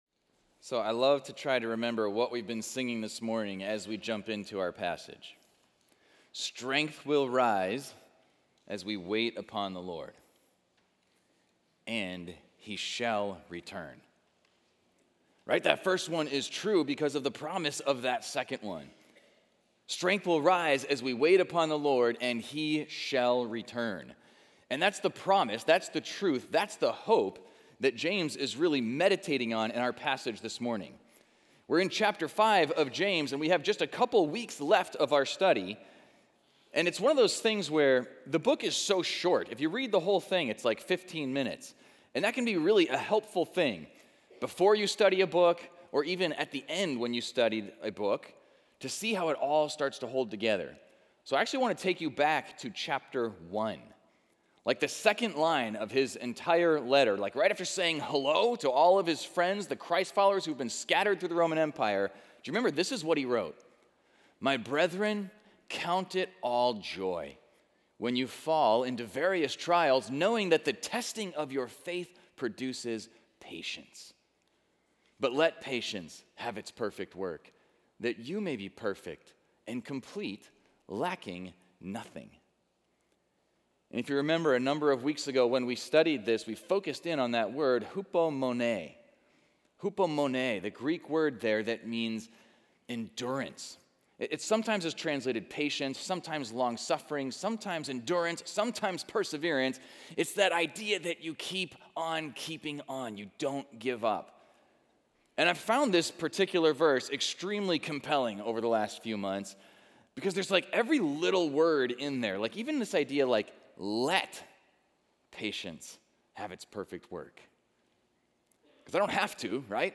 Equipping Service / James: Live and Love Wisely / Endless Endurance